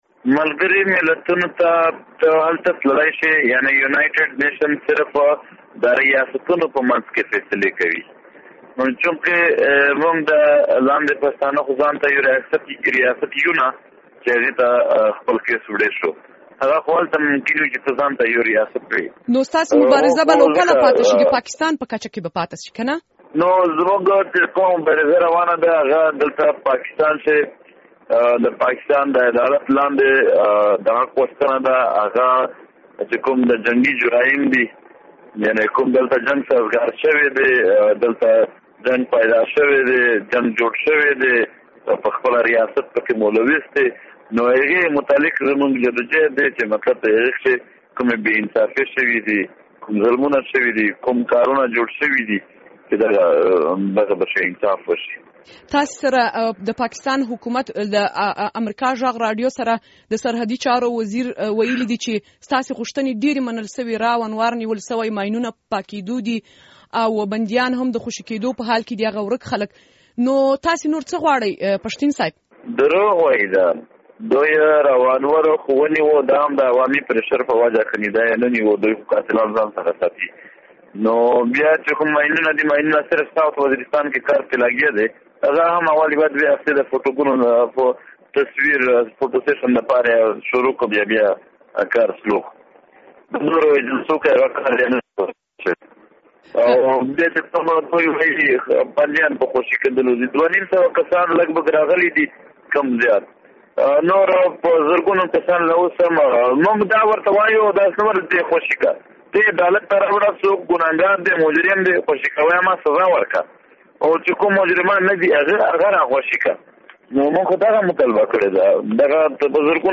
له منظور پښتین سره مرکه